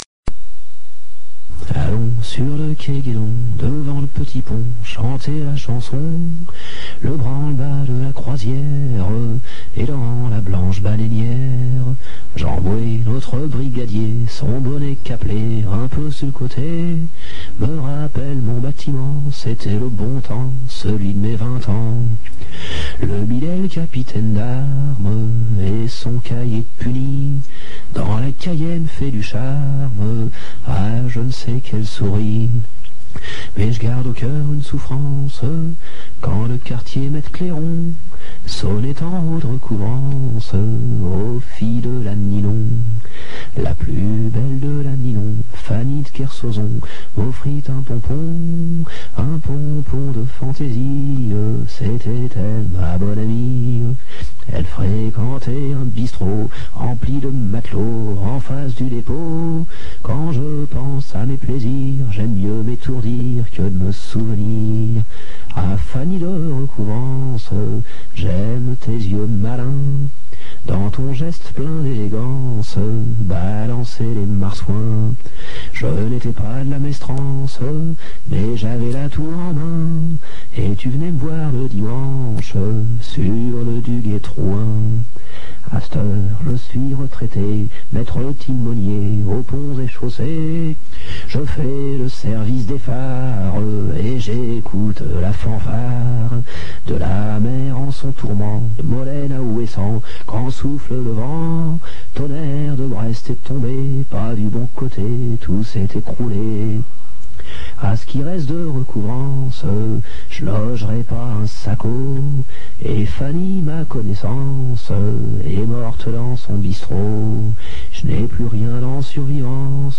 Une chanson traditionnelle de marin (aussi appelée « Fanny de Laninon »), que Renaud chante « a cappella » durant une émission de radio :
Fanny-de-recouvrance-chantée-A-Cappella-dans-une-émission-radio-en-1984.mp3